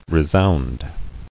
[re·sound]